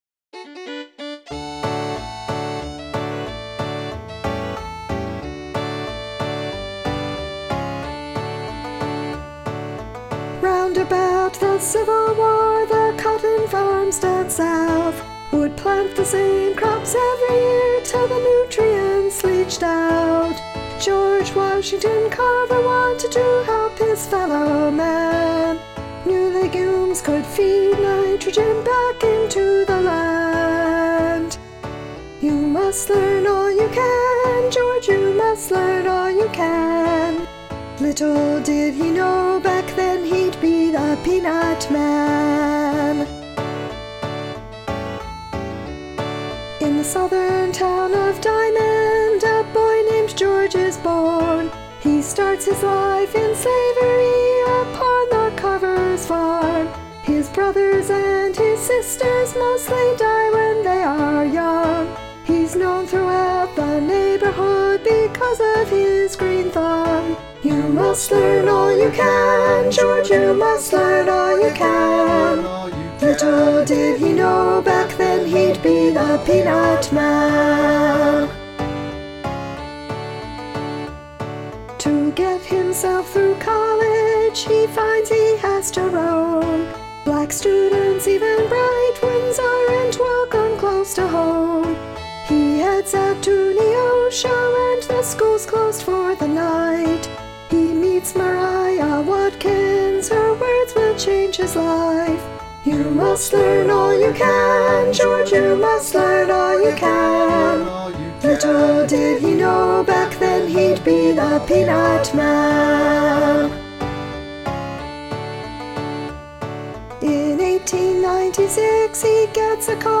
• Peanut Man (world premiere) - A bluegrass-folk fusion song paying tribute to American botanist and inventor George Washington Carver (c1861-1943), who researched and promoted alternative crops to cotton, such as peanuts and sweet potatoes, helping poor farmers provide their own food and other products to improve their quality of life.